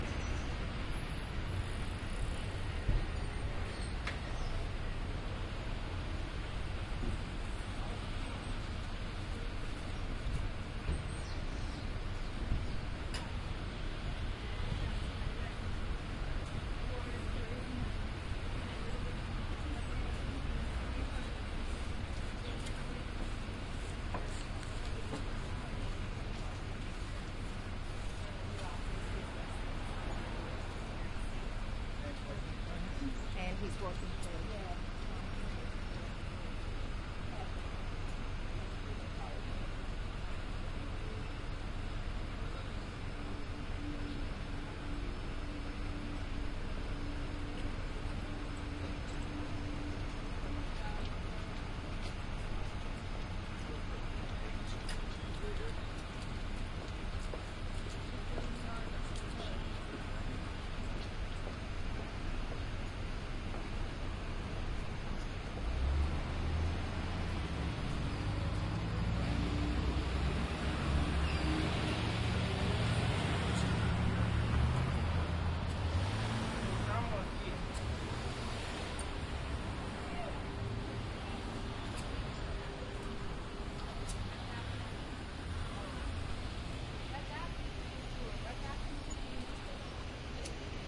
鸟类 " 布谷鸟
描述：这个样本也是在2006年5月28日在丹麦埃斯比约市附近录制的，使用的是Soundman OKM II和夏普IMDR 420 MD录音机。除了最后的一些飞机噪音，这是一个很好的杜鹃和一些其他鸟类的录音。在丹麦确实有一些鸟鸣，就像我一年前的另一个样本。
标签： 双耳 杜鹃 丹麦 现场录音
声道立体声